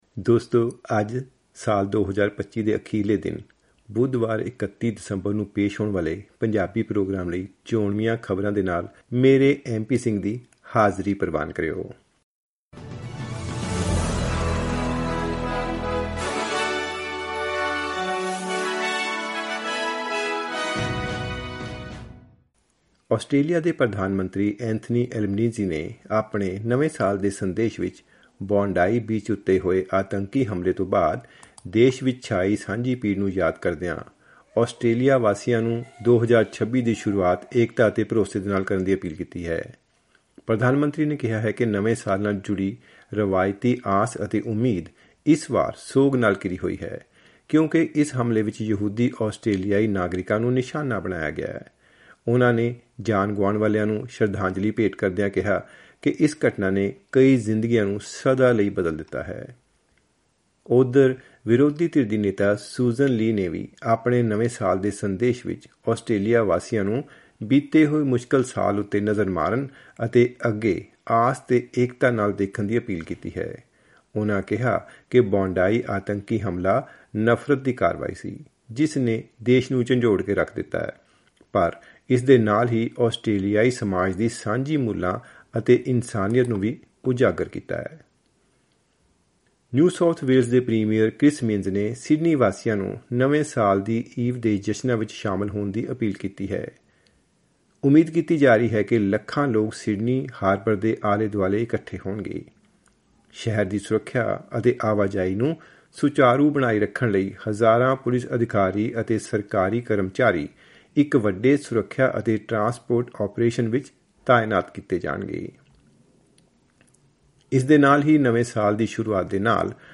ਖ਼ਬਰਨਾਮਾਂ: 2026 ਦਾ ਸਵਾਗਤ ਏਕਤਾ, ਨਵੀ ਨੀਤੀਆਂ ਅਤੇ ਸੁਰੱਖਿਆ ਚੇਤਾਵਨੀਆਂ ਨਾਲ